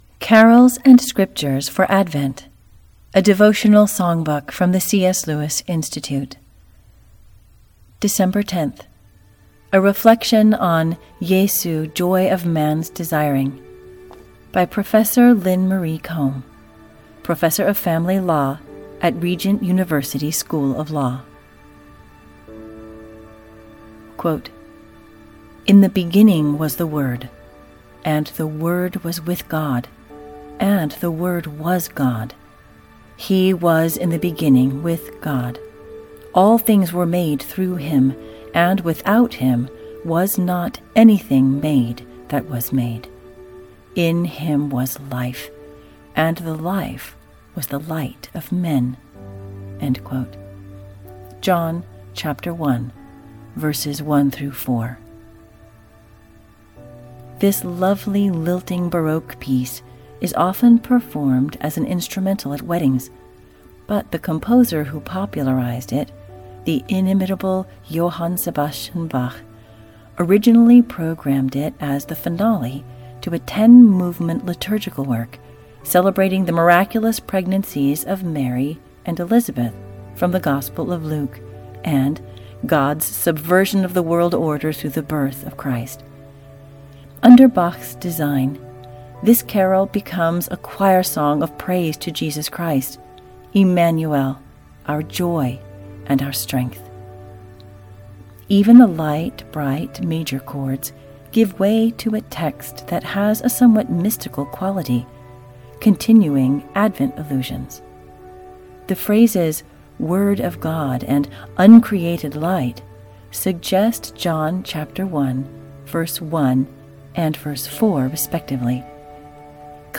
Explore 30 beloved Christmas carols accompanied by original piano recordings. Each carol is paired with a passage of Scripture, a short reflection, and master artwork for the Advent Season.